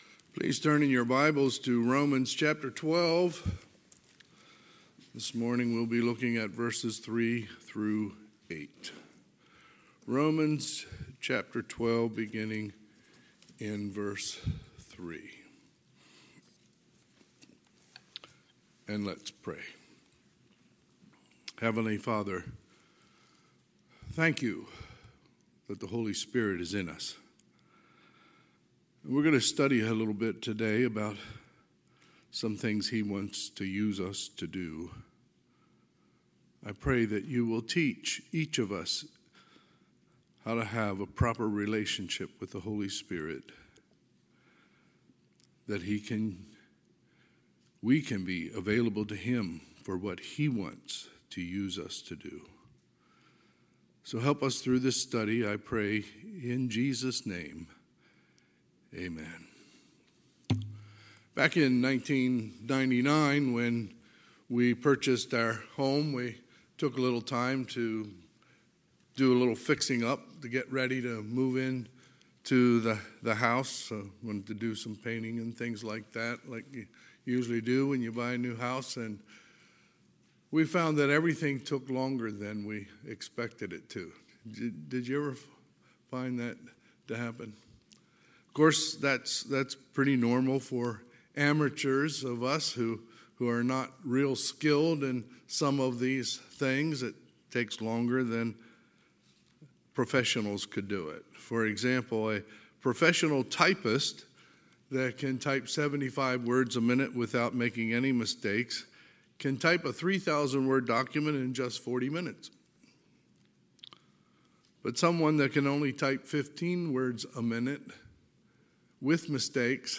Sunday, July 17, 2022 – Sunday AM
Sermons